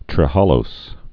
(trĭ-hälōs, -lōz)